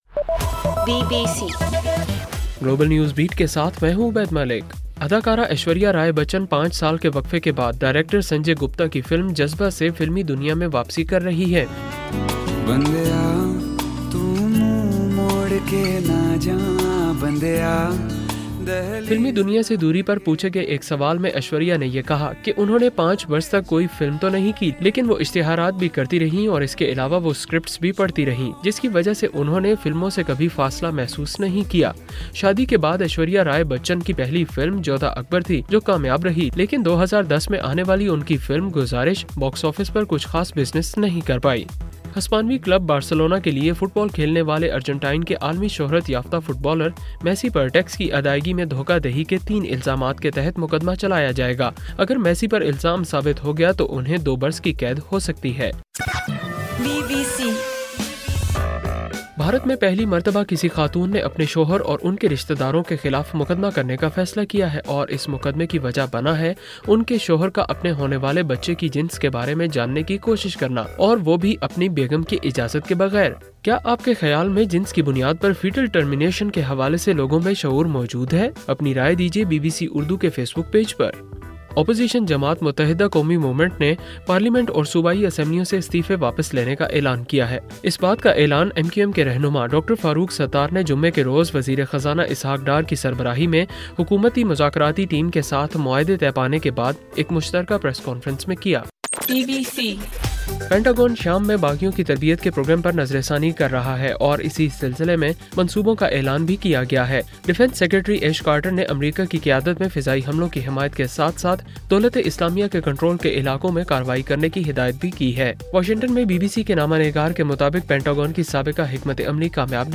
اکتوبر 9: رات 11 بجے کا گلوبل نیوز بیٹ بُلیٹن